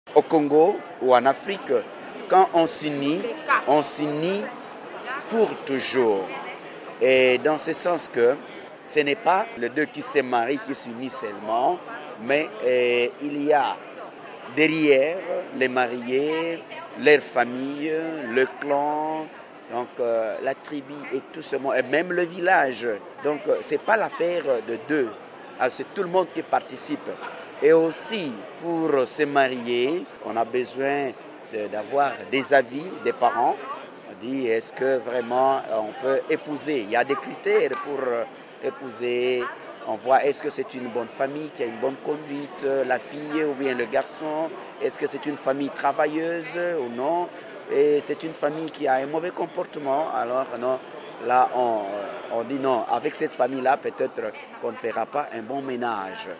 Il témoigne du sens de la famille dans son pays.